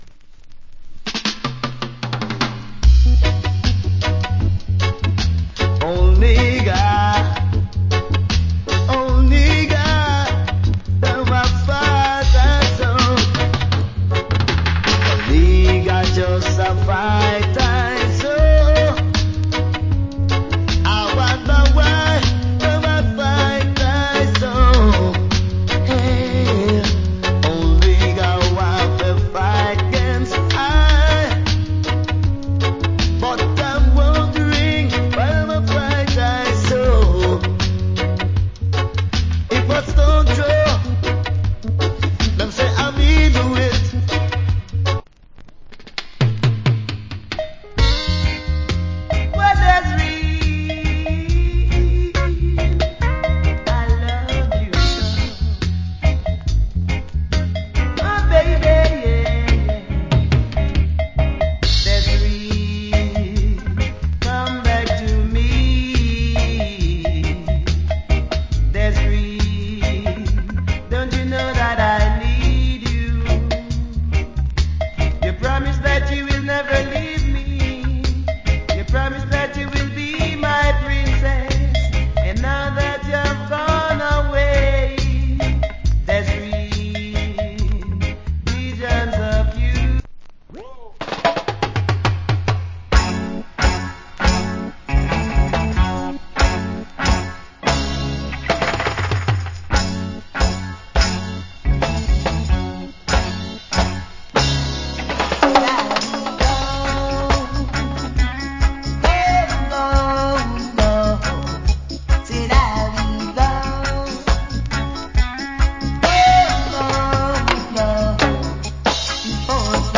Early 80's Roots.